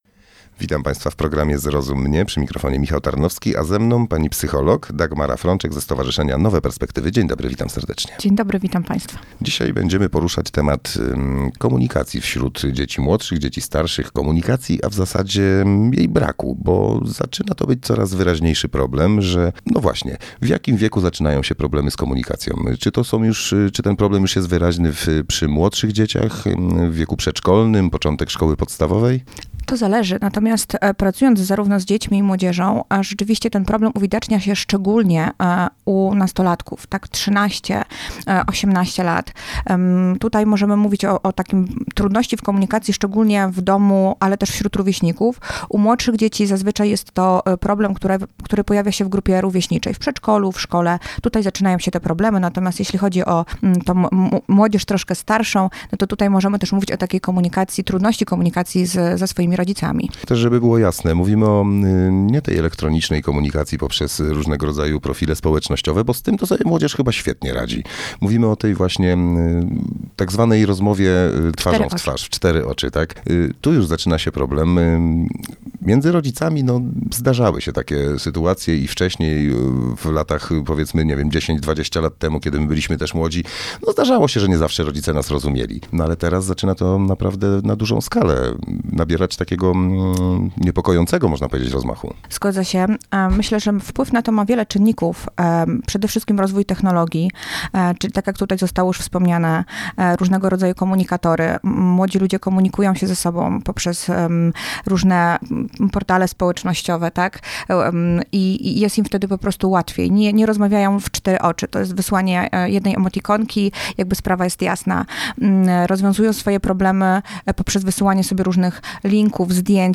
Zrozum mnie – program parentingowy na antenie Radia Radom